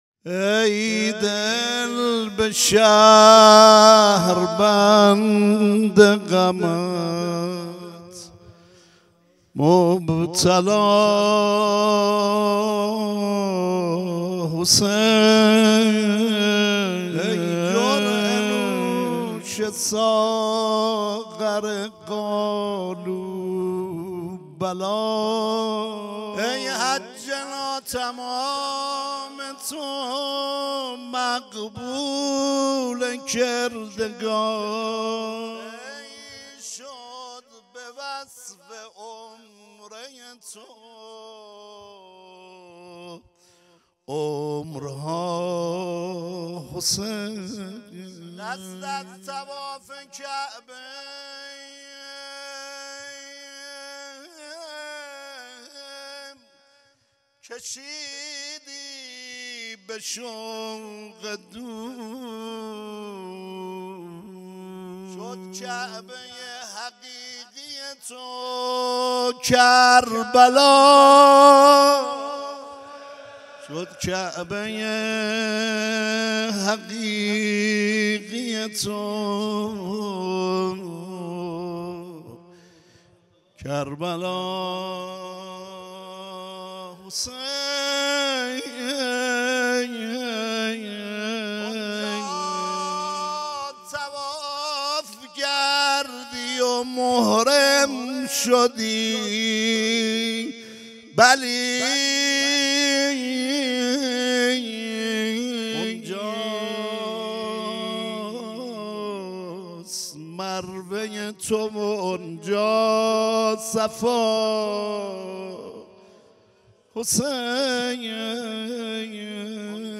شهادت حضرت زینب (س) هیئت موکب لواء الزینب